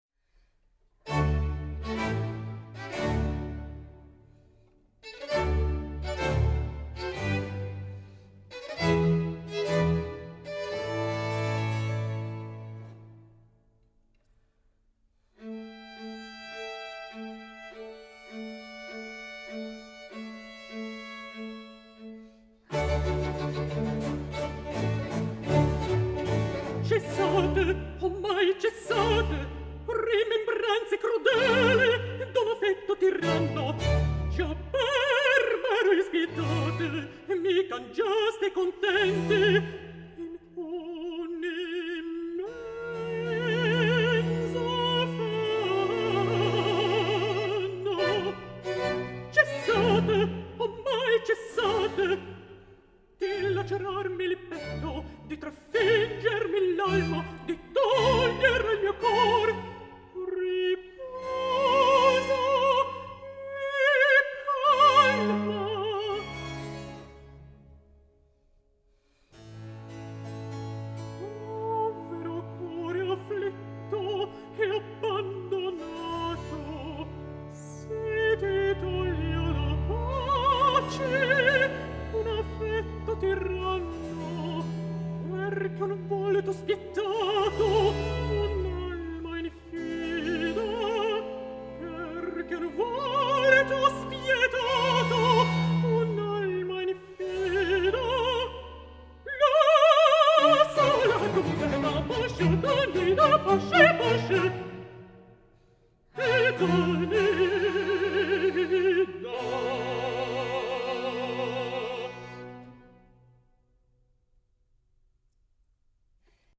Countertenor
Orchestre
Recitative